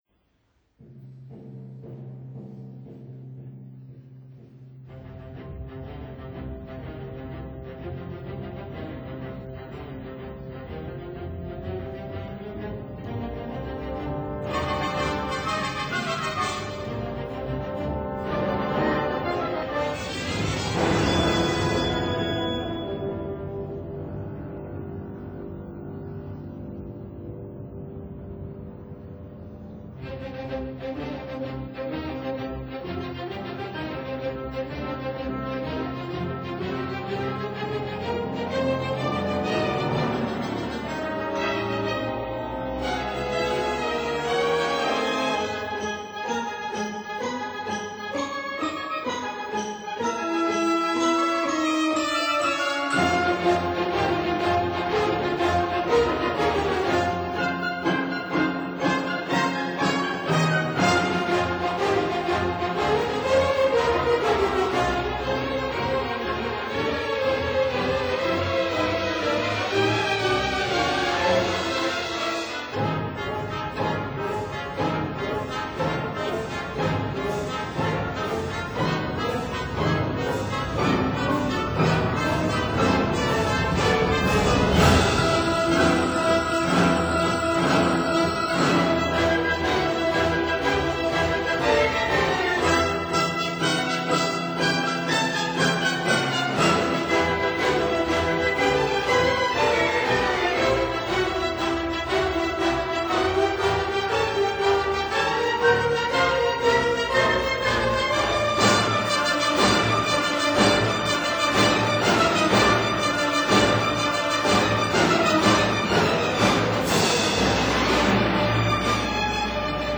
for piano and orchestra